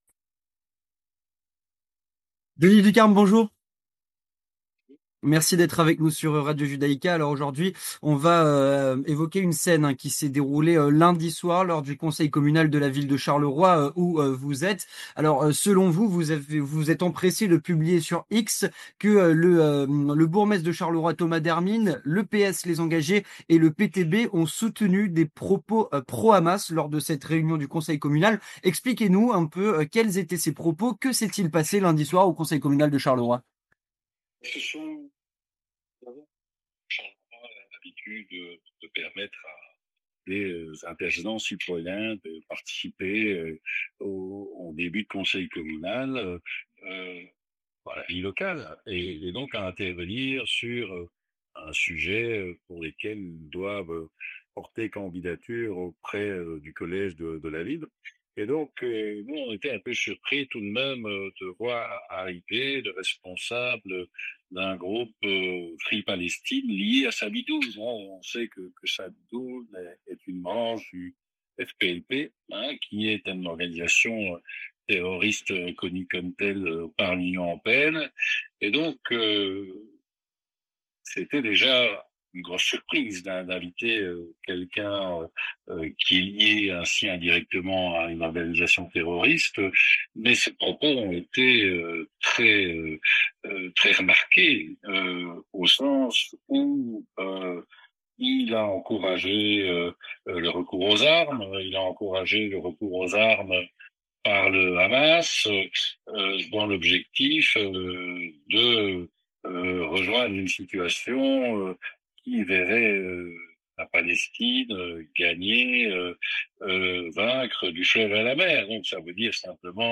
Avec Denis Ducarme, député féderal et Président provincial du MR du Hainaut